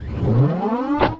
New Door Sounds